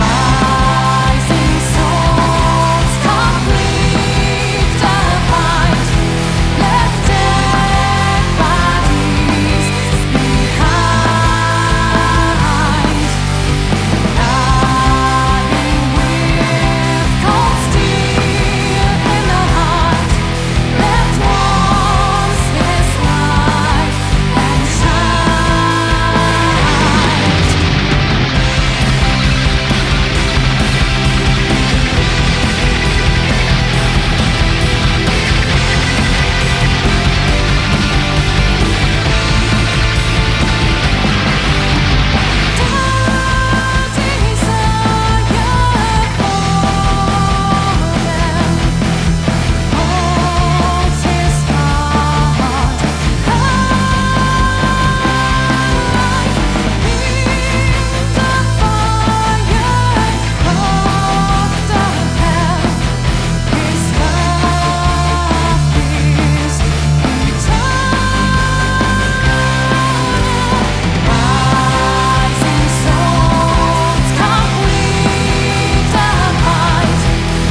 Guitars
Keyboard
Drums